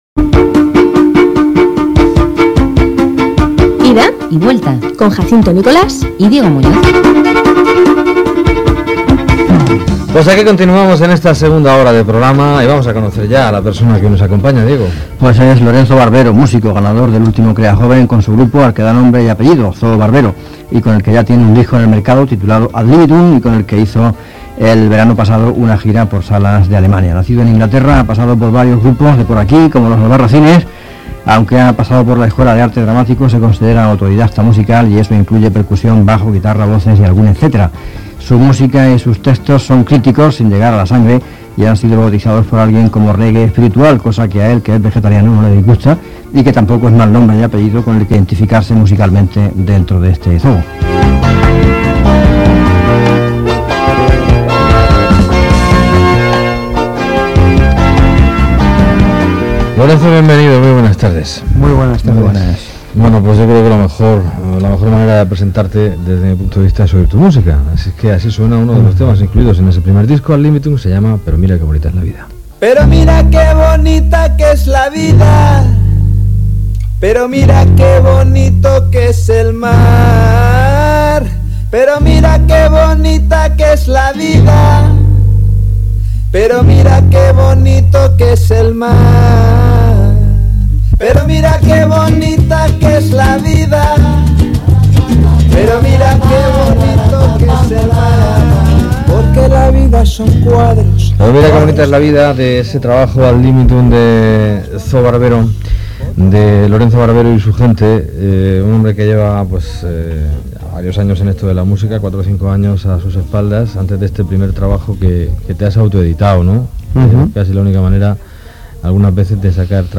entrevista_ida_y_vuelta.mp3